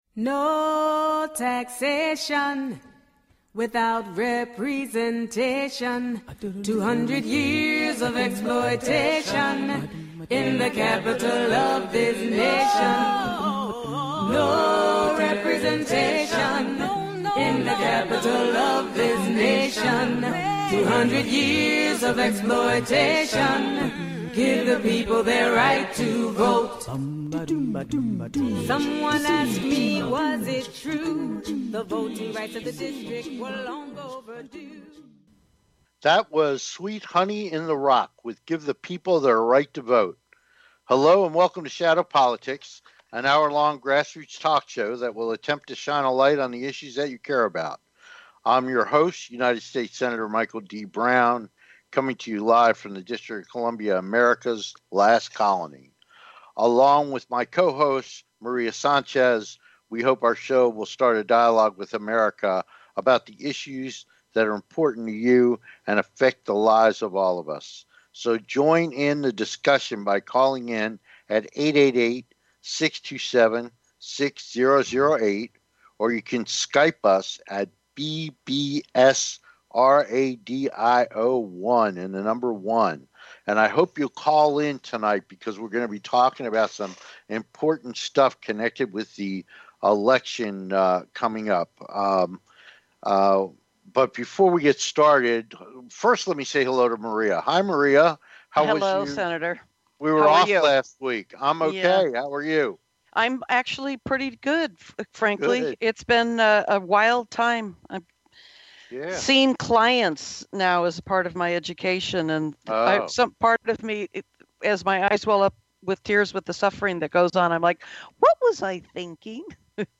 Talk Show Episode, Audio Podcast, Shadow Politics and What will be America's future? on , show guests , about Future of America,Clinton vs Trump,presidential election,divided nation,Corruption in politics, categorized as History,Kids & Family,News,Politics & Government,Self Help,Society and Culture,Variety
Shadow Politics is a grass roots talk show giving a voice to the voiceless.